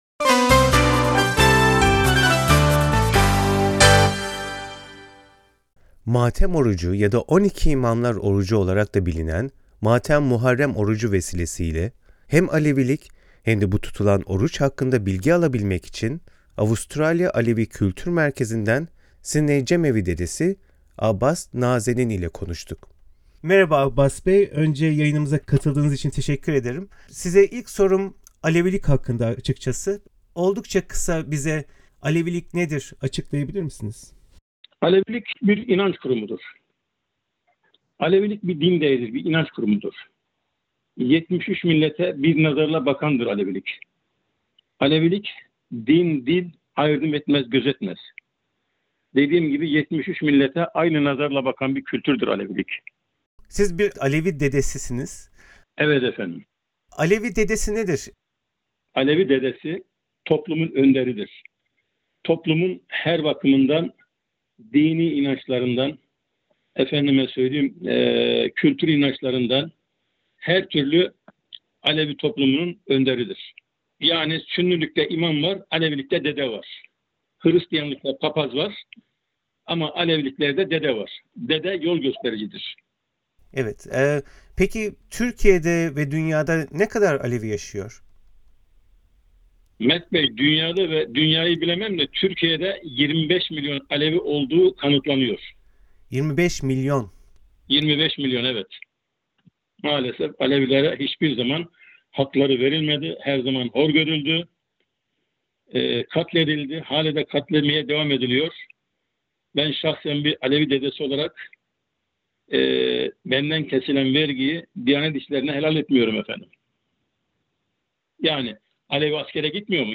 Matem orucu; bir Alevi dedesiyle söyleşi